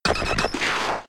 Cri de Taupiqueur K.O. dans Pokémon X et Y.